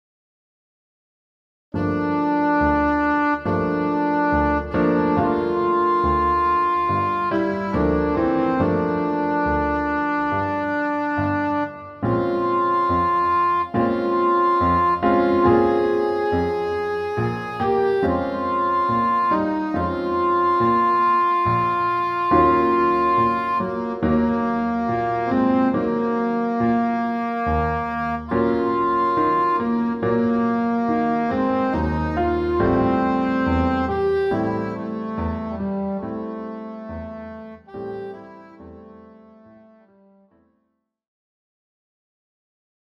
the clarinet version is entirely within the low register
Key: G minor